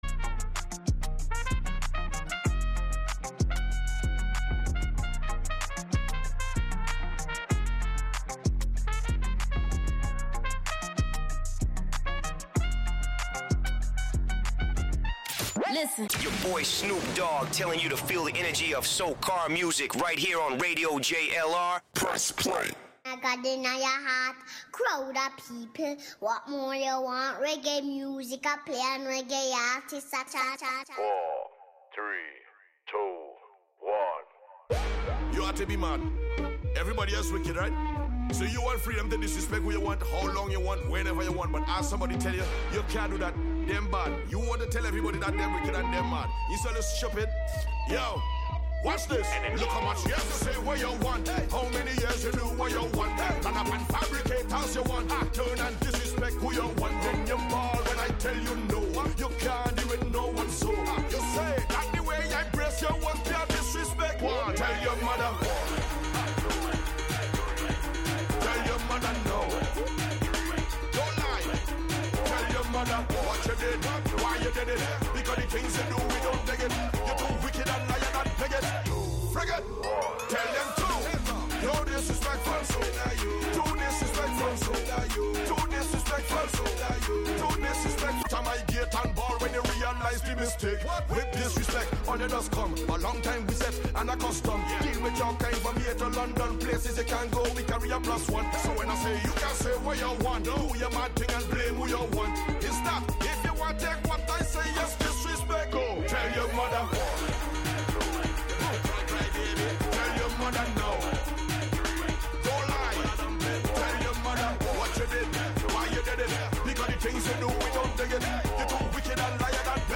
Radio station
Genre: Reggae , Hip Hop , Freeform/DJ